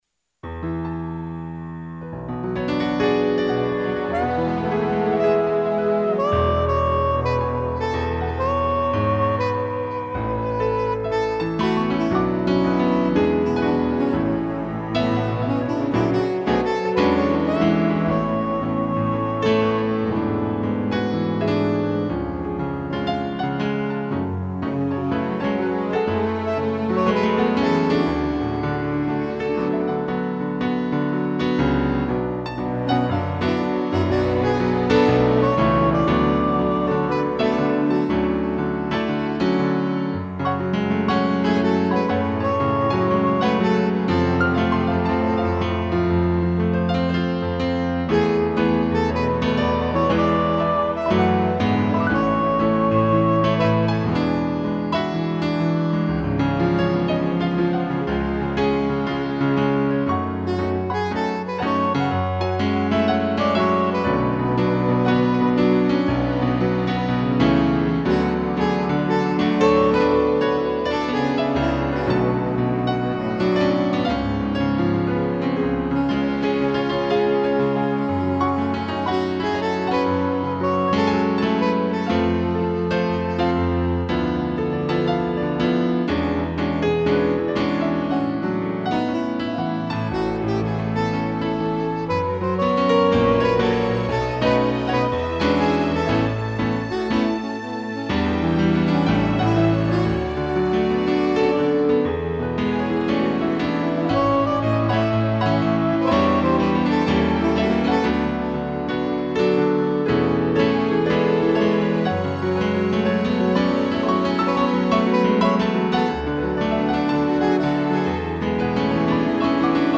2 pianos, sax e cello
(instrumental)